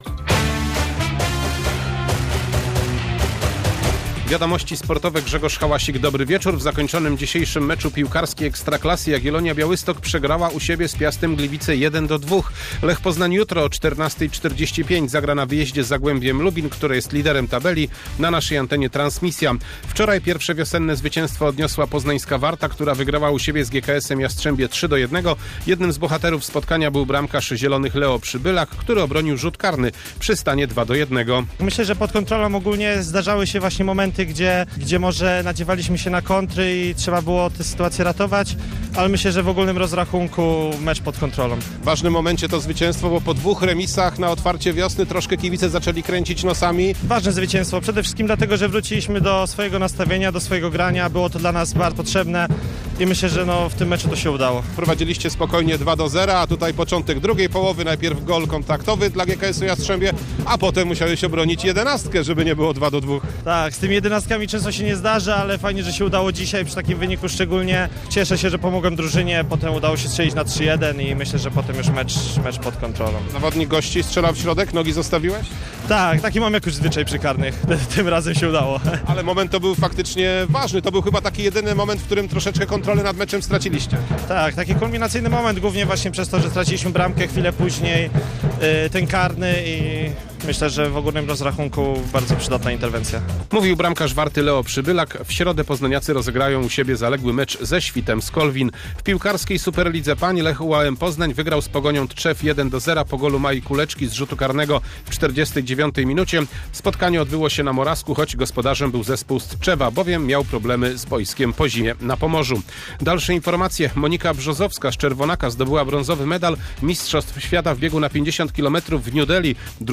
14.03.2026 SERWIS SPORTOWY GODZ. 19:05